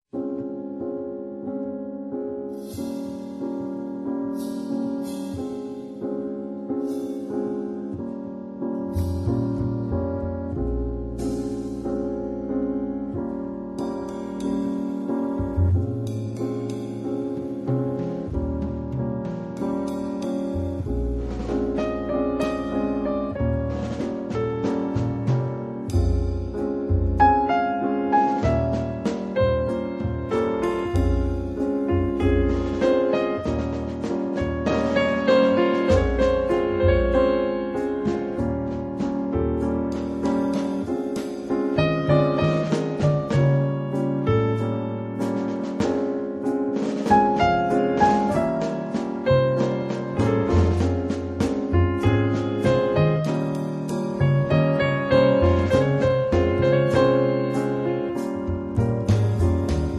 piano
basso
batteria